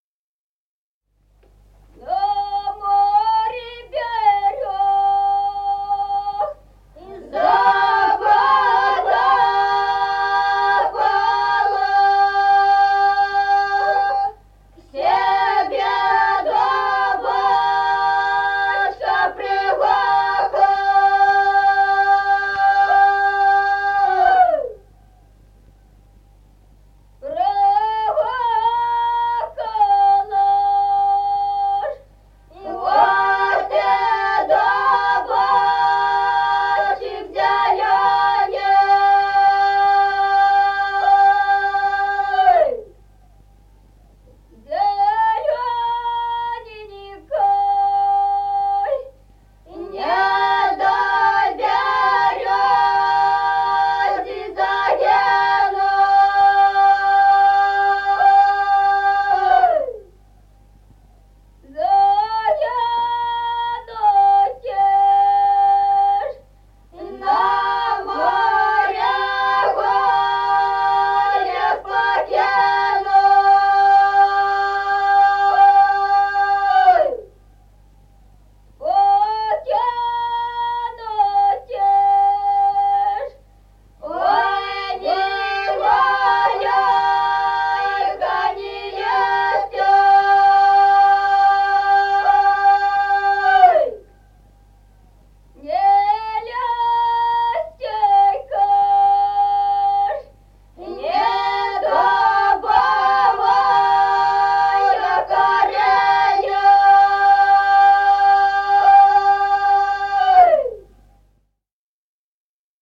Народные песни Стародубского района «На море берёза», весняная девичья.
1953 г., с. Мишковка.